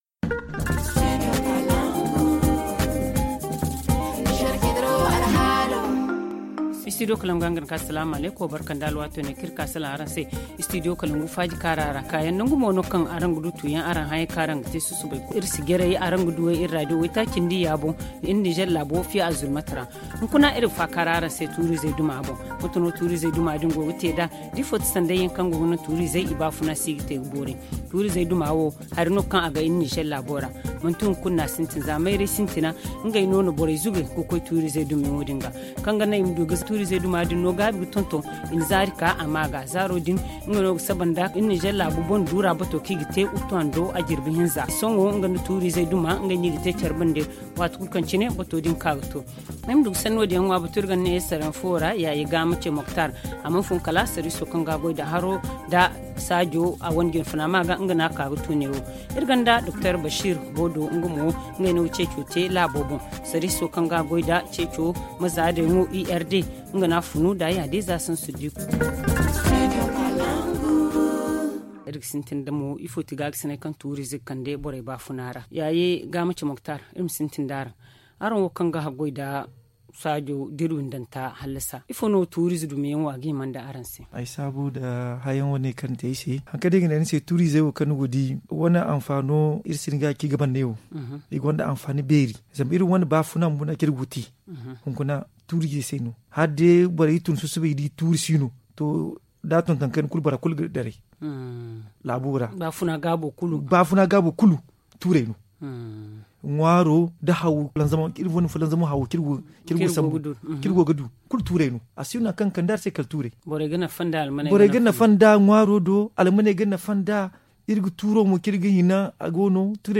Et la troisième partie de ce forum s’appesantira sur comment réussir à planter beaucoup d’arbres au Niger et à bien les entretenir. Pour en débattre